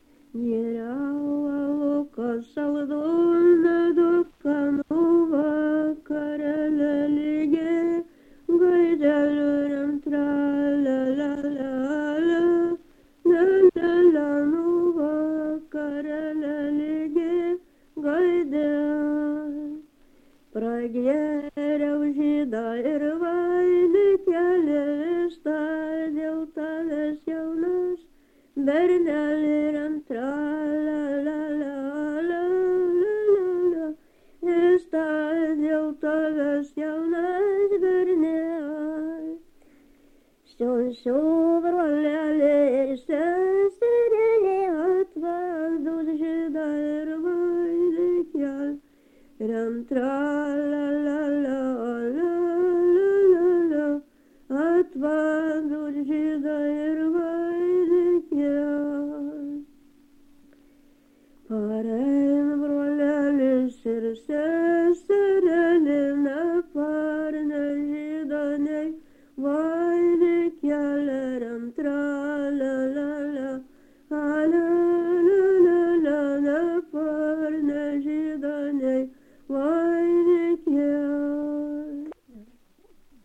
Subject daina
Erdvinė aprėptis Struikai
Atlikimo pubūdis vokalinis